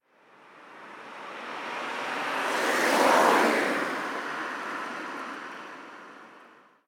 Coche pasando rápido 1
coche
rápido
Sonidos: Transportes